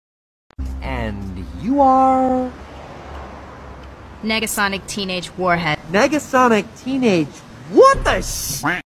I love this quack sound effect 💔